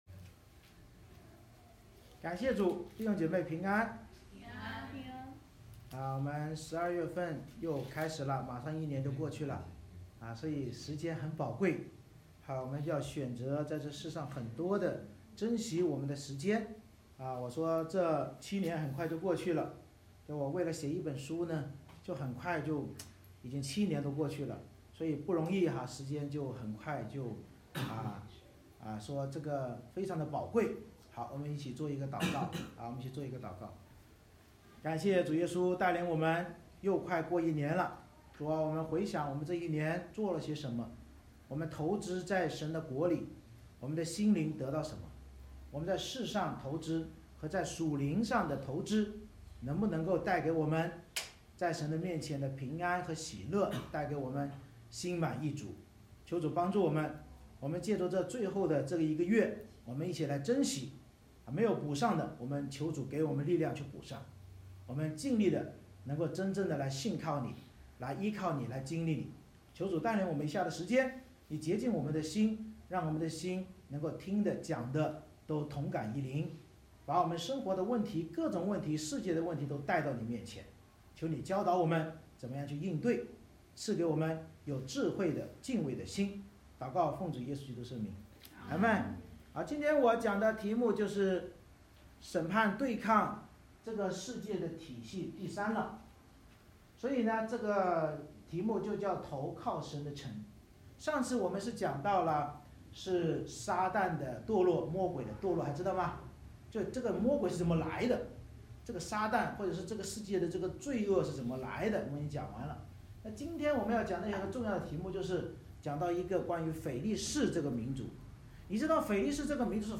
以赛亚书14:28-32 Service Type: 主日崇拜 先知领受非利士的默示，教导我们：魔鬼堕落后首先以古蛇来引诱始祖及后人背叛神成为依靠世界城墙的外邦人，但主必建立圣城搭救那寻求祂的子民。